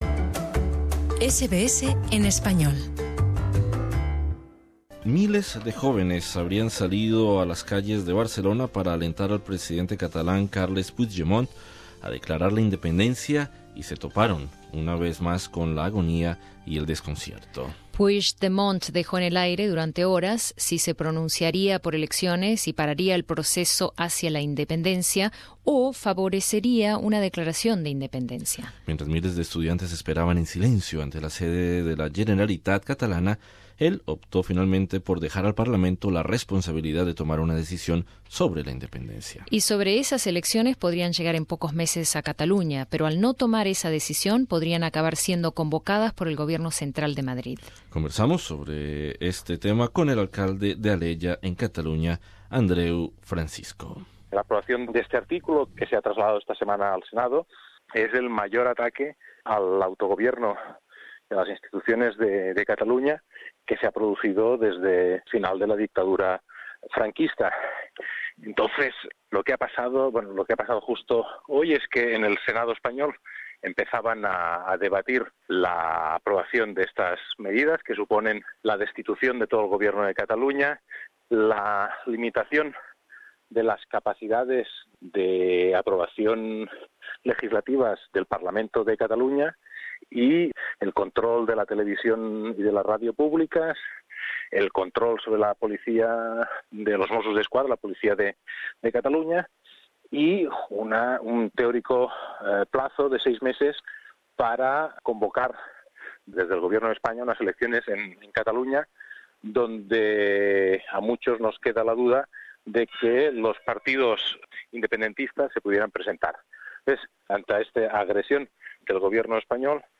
Entrevista con el alcalde de Alella en Cataluña, Adreu Francisco.